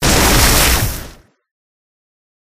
Ice10.ogg